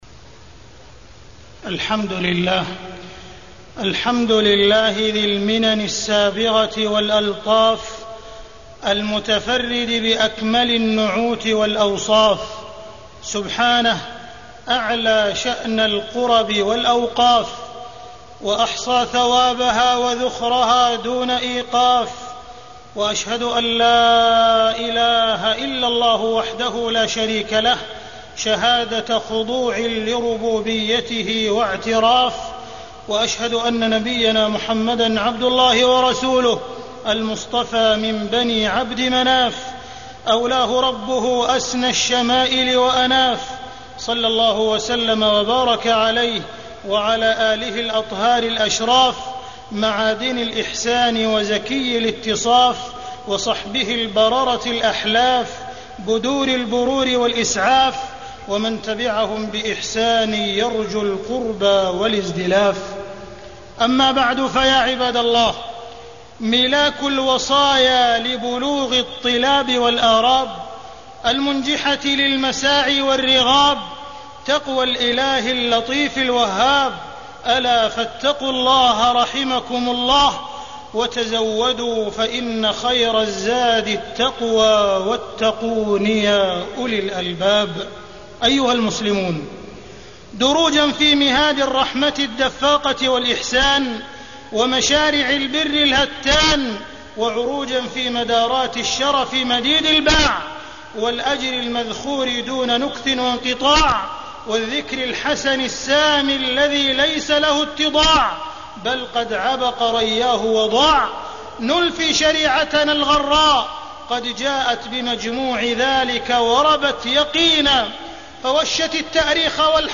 تاريخ النشر ١٠ ذو القعدة ١٤٢٧ هـ المكان: المسجد الحرام الشيخ: معالي الشيخ أ.د. عبدالرحمن بن عبدالعزيز السديس معالي الشيخ أ.د. عبدالرحمن بن عبدالعزيز السديس الأوقاف ونصيبك منها